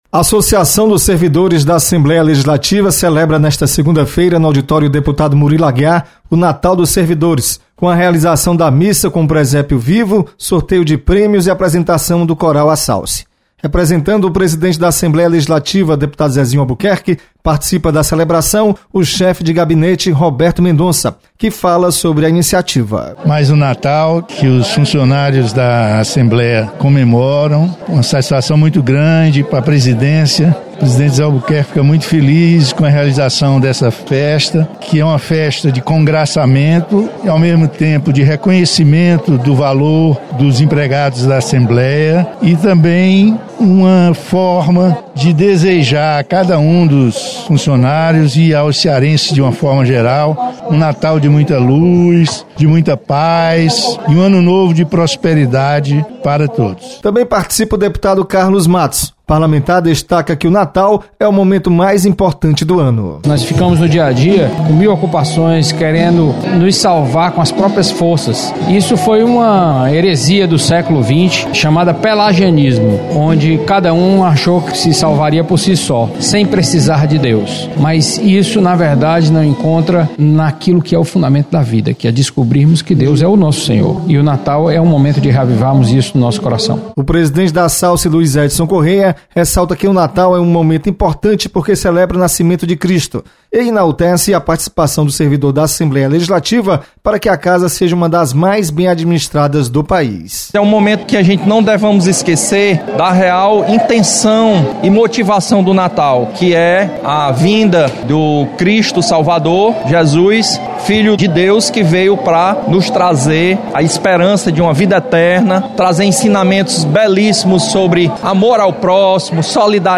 Associação dos Servidores da Assembleia realiza festa de confraternização de fim de ano. Repórter